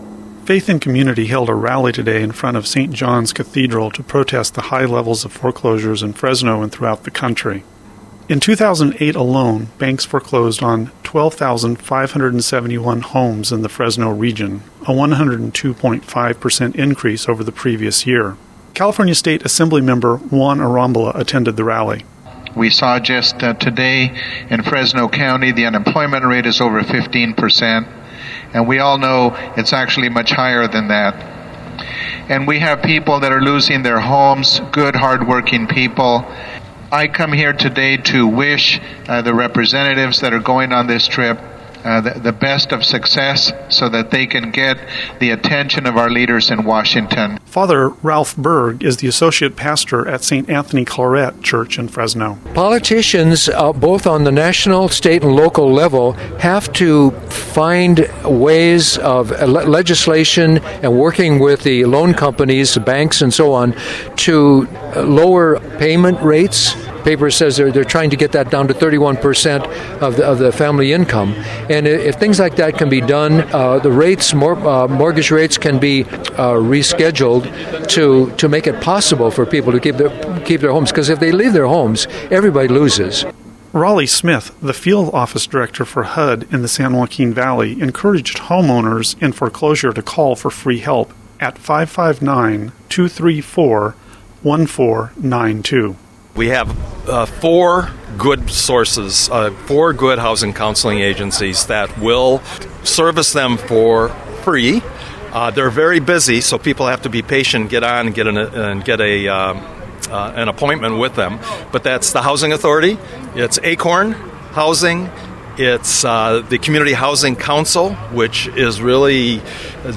Faith in Community held a rally today in front of Saint John’s Cathedral to protest the high level of forecloses in Fresno and throughout the country.
§2:45 minute audio from the rally